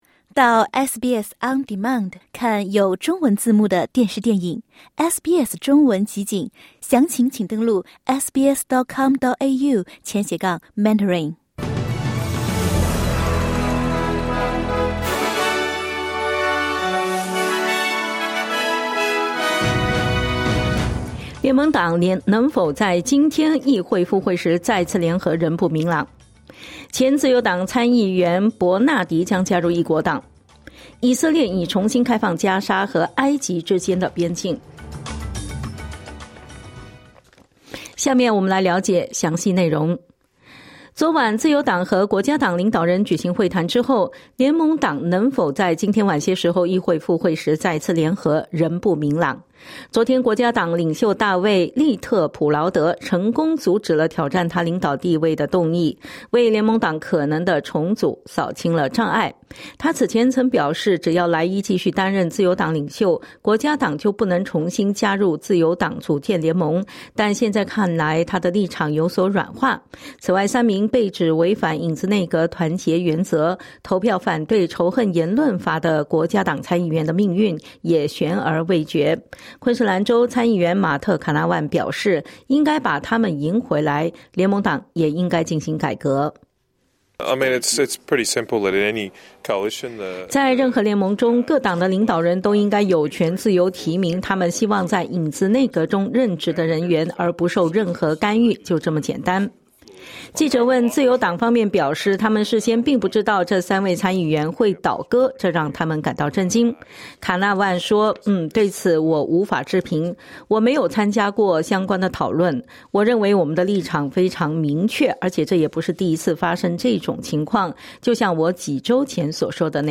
【SBS早新闻】联盟党能否再次联合仍不明朗